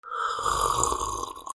お茶をすする 1